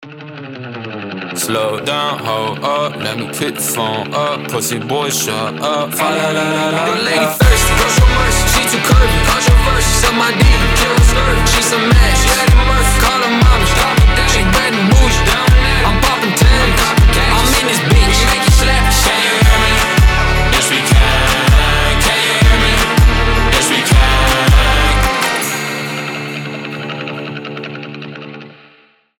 • Качество: 320, Stereo
веселые
alternative
Забавный альтернативный рэпчик